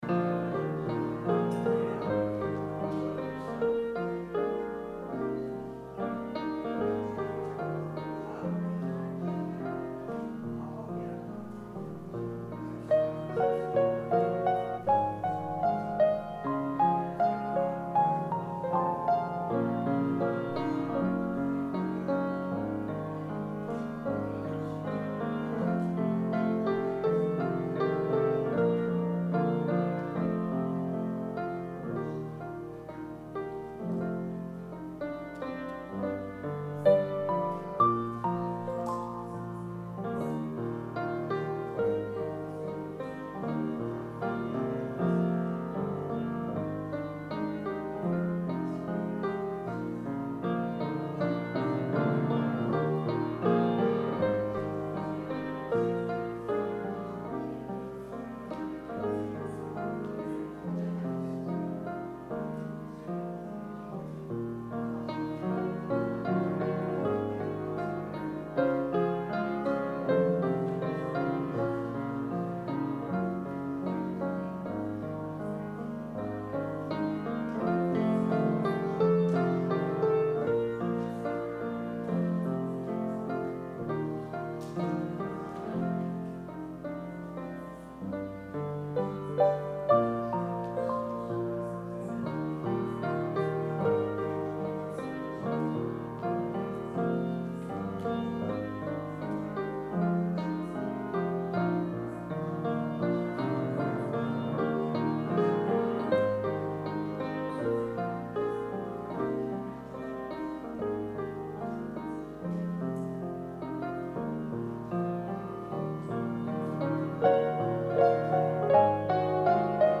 Sermon – July 14, 2019 – Advent Episcopal Church